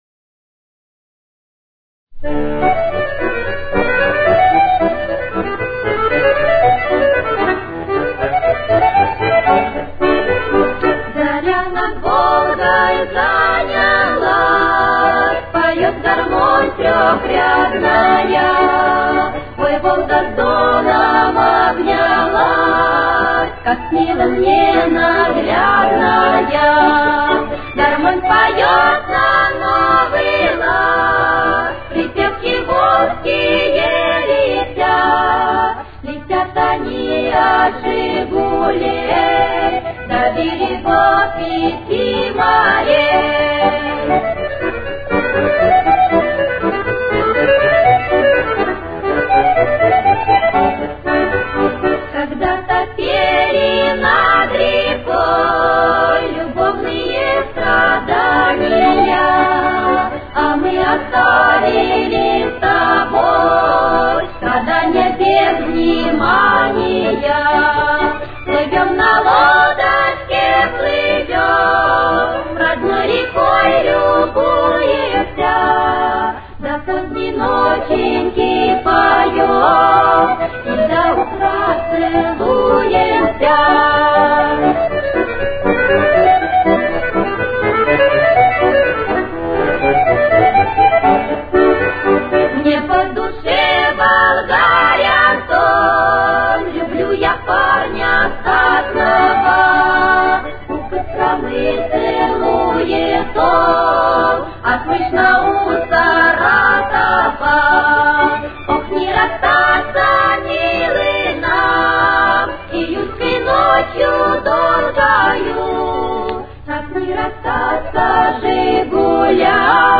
Темп: 119.